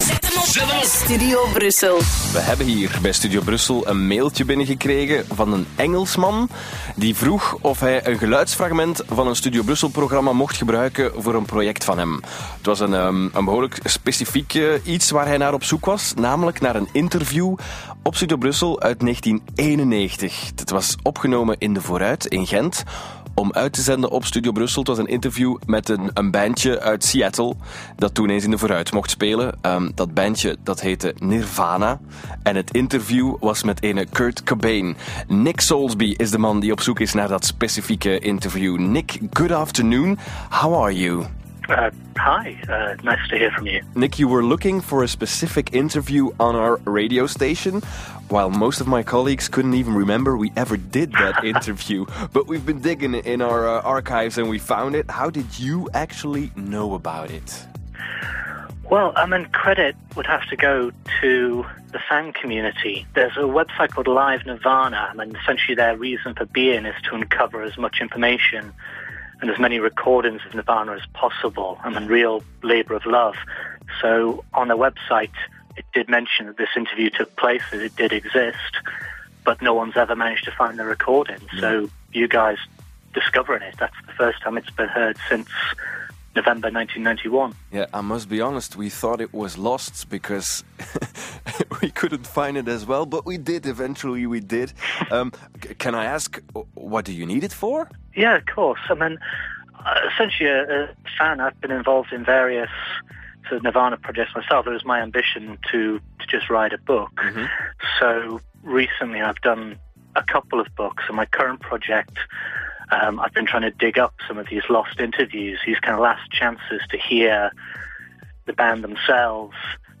Here’s the MP3 of me breathing heavily down the phone, using the present-tense rather than the past-tense when mentioning Kurt Cobain, giggling…